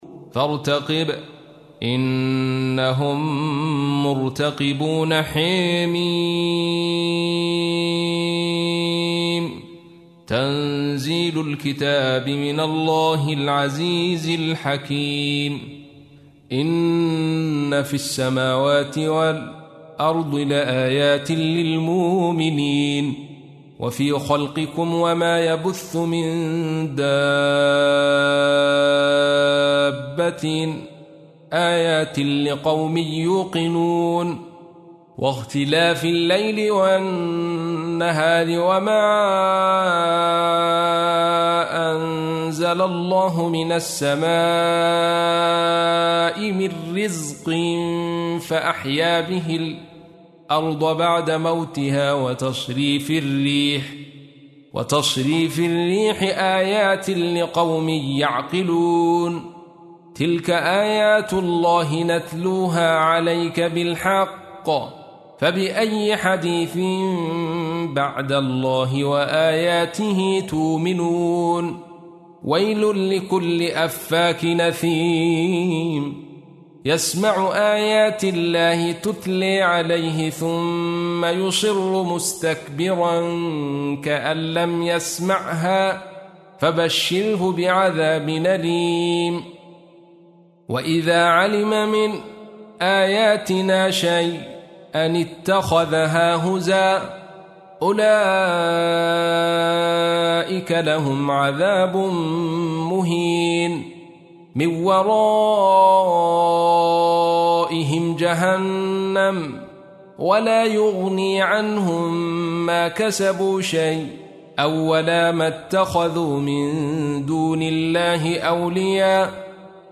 تحميل : 45. سورة الجاثية / القارئ عبد الرشيد صوفي / القرآن الكريم / موقع يا حسين